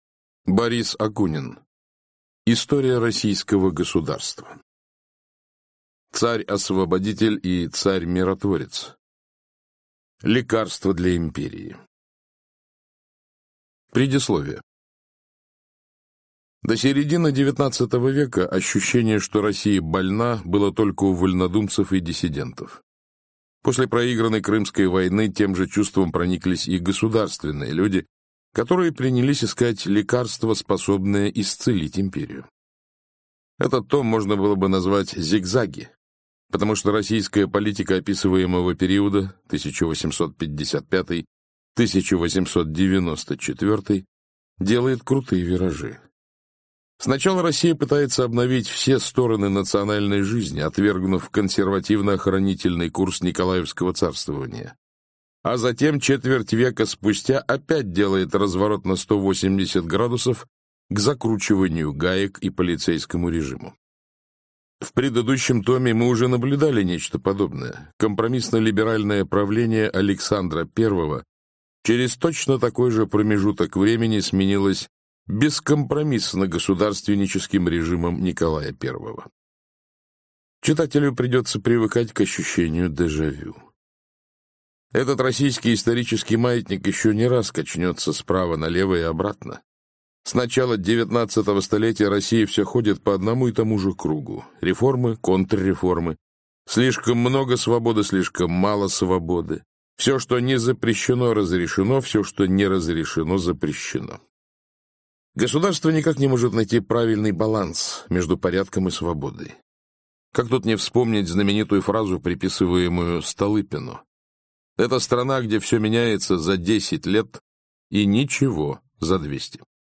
Аудиокнига Лекарство для империи. История Российского государства. Царь-освободитель и царь-миротворец - купить, скачать и слушать онлайн | КнигоПоиск